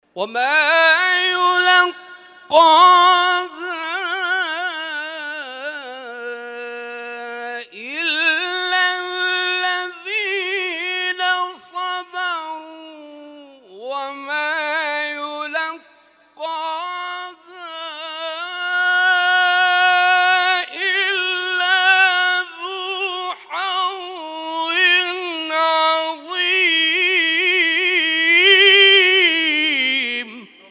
تلاوت‌های محفل هفتگی انس با قرآن آستان عبدالعظیم(ع) + دانلود
محفل هفتگی انس با قرآن در آستان عبدالعظیم(ع) + صوت